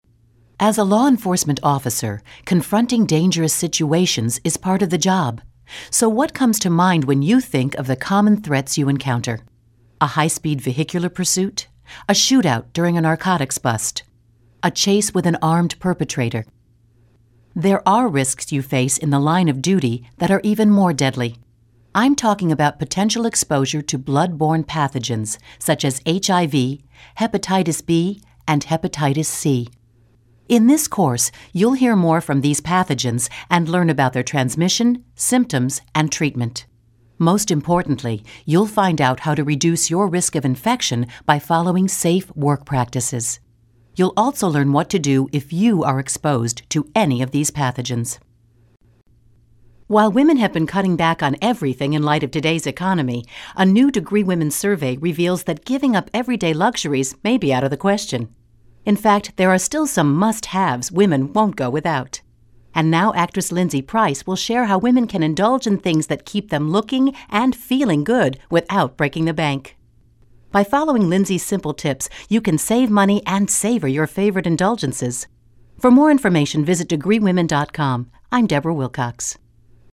narration : women